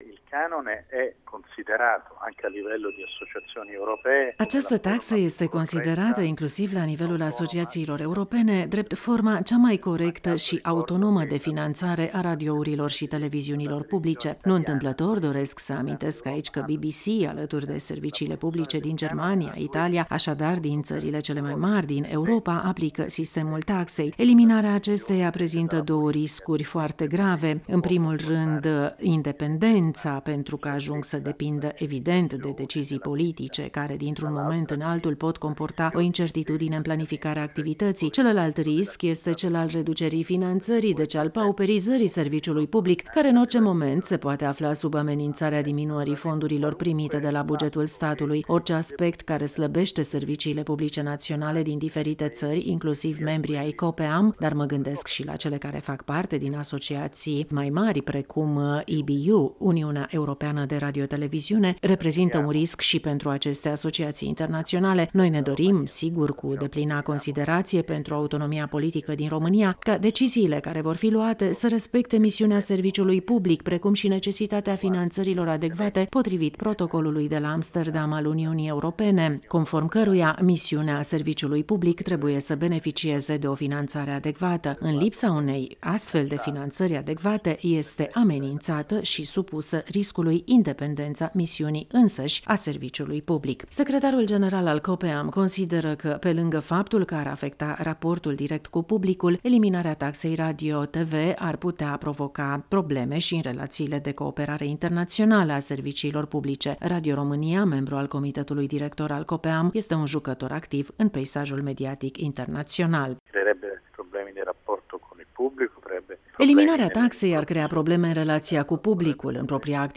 a declarat intr-un interviu pentru Radio România